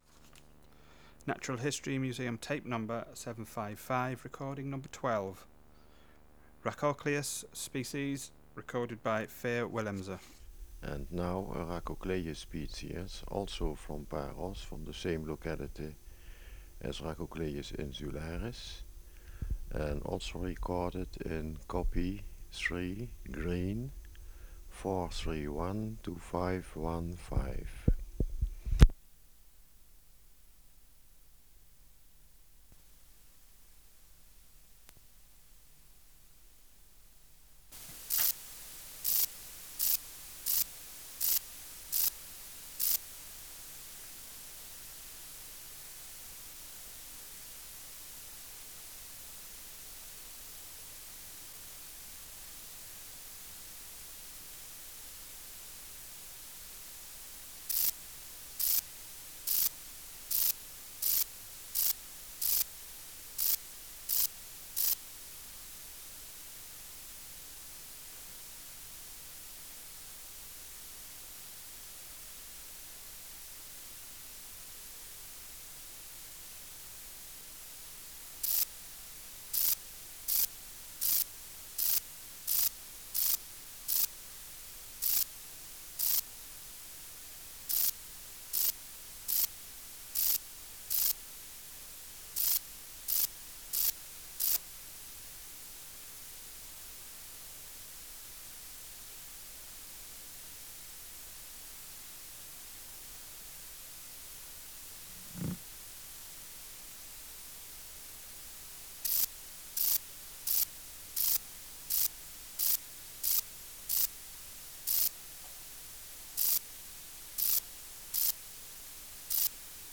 587:12 Rhacocleis sp.
Air Movement: Nil
Substrate/Cage: In cage
Microphone & Power Supply: AKG D202E (LF circuit off) Distance from Subject (cm): 3